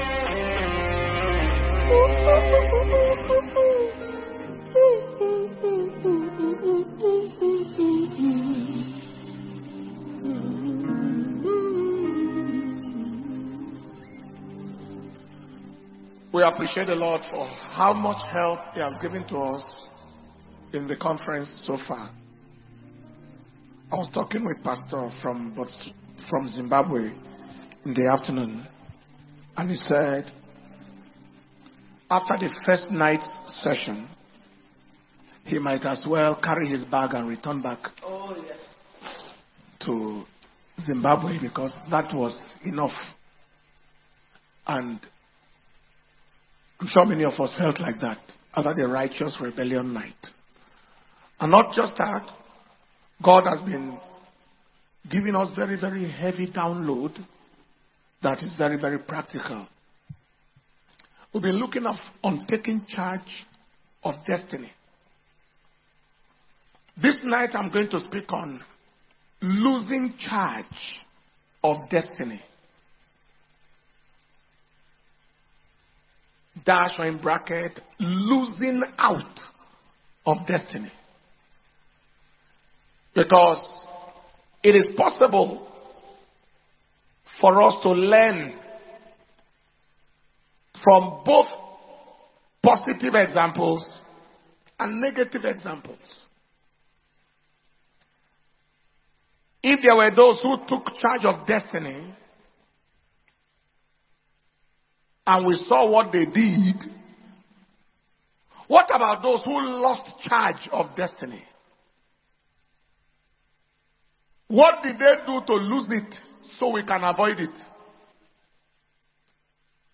Destiny Recovery Convention Thursday 29th 2025 – Day 3 Evening Session